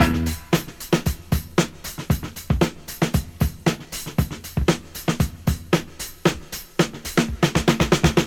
• 116 Bpm Modern Drum Loop Sample E Key.wav
Free drum loop sample - kick tuned to the E note. Loudest frequency: 2079Hz
116-bpm-modern-drum-loop-sample-e-key-Vir.wav